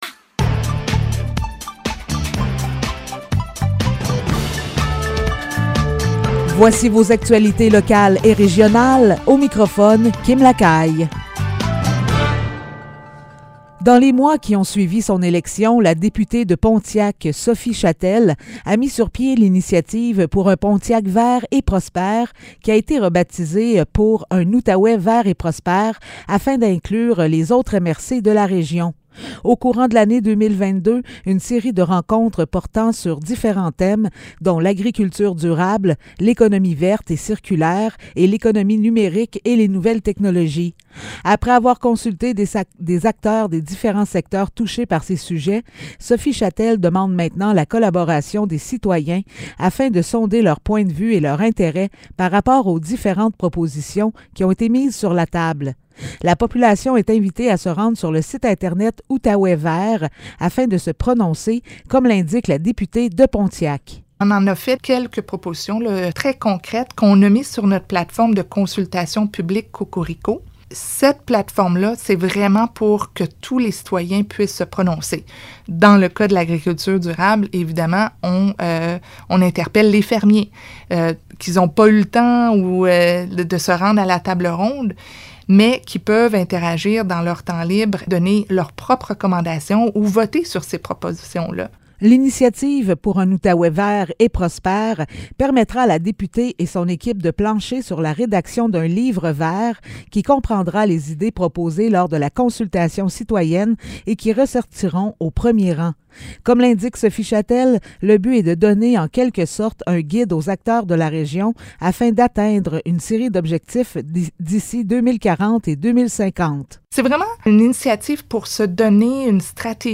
Nouvelles locales - 8 août 2022 - 15 h